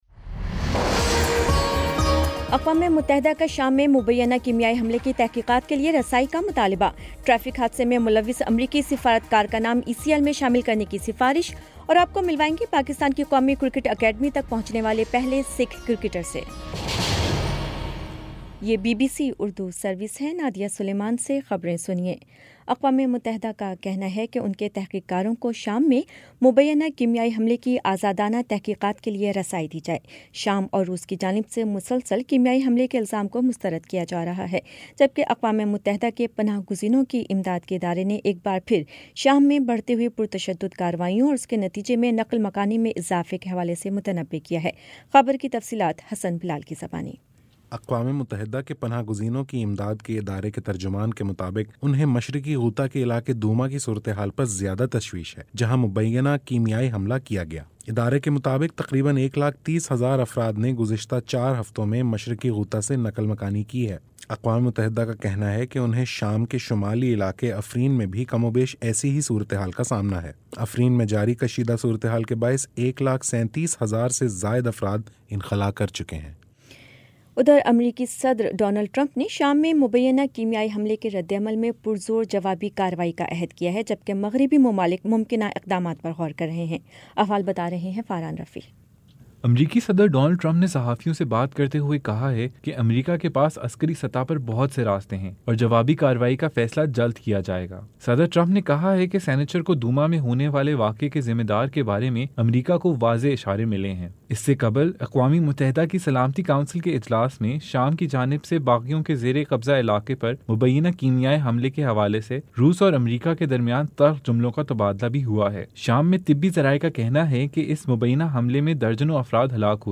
دس منٹ کا نیوز بُلیٹن روزانہ پاکستانی وقت کے مطابق شام 5 بجے، 6 بجے اور پھر 7 بجے۔